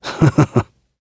Clause_ice-Vox-Laugh.wav